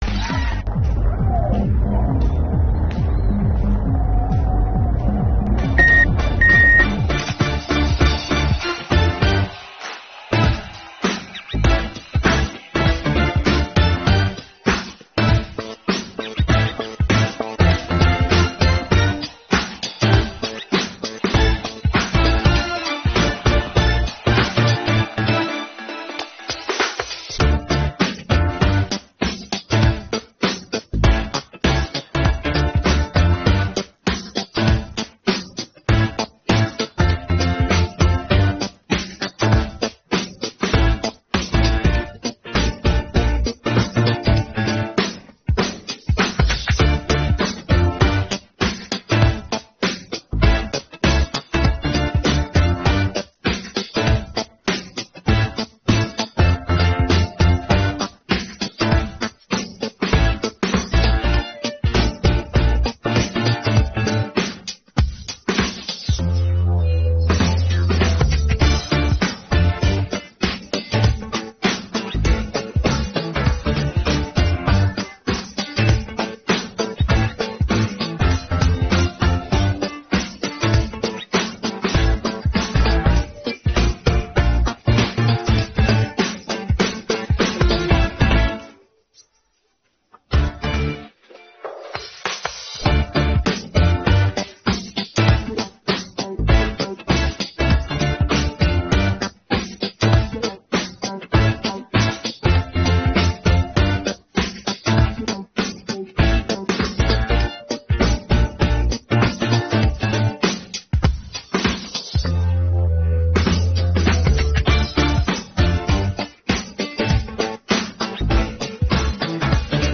pop караоке
українські караоке